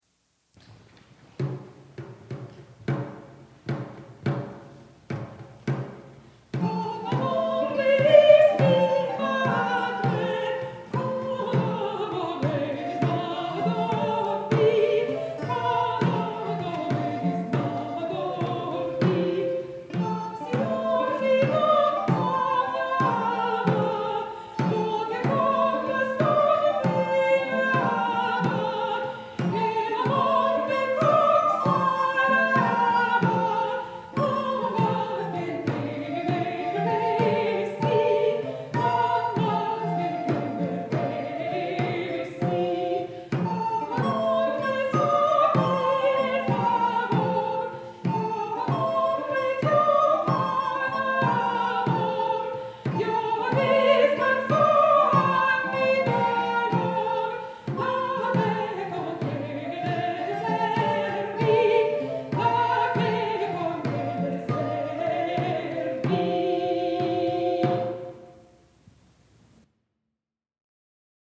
This lively villancico is one of the 468 pieces found in the ‘Cancionero Musical de Palacio’, edited and transcribed by Higinio Anglés and published by the Instituto Español de Musicología in the series ‘Monumentos de la Musica Española’ Vol. 10. “Con amores” is notable for it’s meter–5/4 in modern notation–quite rare in 15th and 16th century music.
soprano